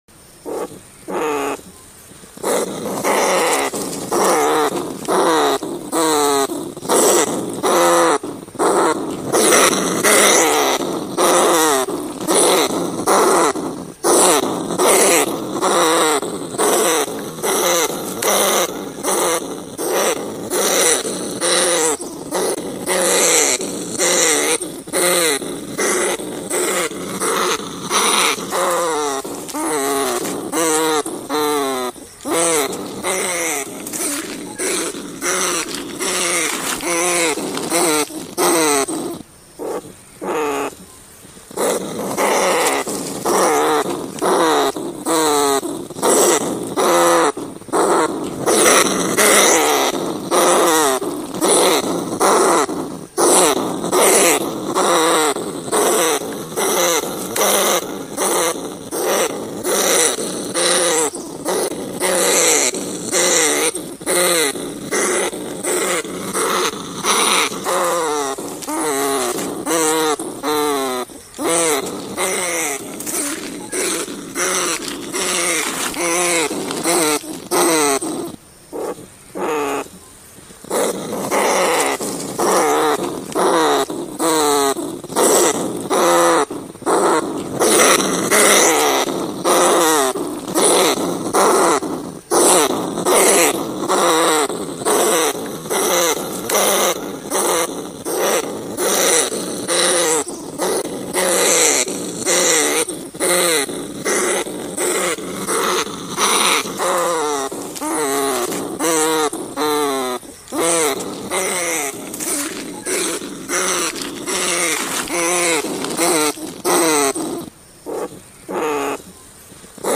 คุณสามารถดาวน์โหลดเสียงหนูพุกต่อกลางคืน mp3 ได้จากลิ้งค์ที่อยู่ใต้คลิปครับ มันเป็นเสียงจริง ชัดแจ๋ว 100% ไม่มีโฆษณาแทรก และเข้าไว 100% สำหรับหนูนา เสียงหนูพุกนี้สำหรับต่อกลางคืนและเสียงล่อหนูพุก
เสียงหนูพุกต่อกลางคืน
tieng-chuot-dong-keu-vao-ban-dem-th-www_tiengdong_com.mp3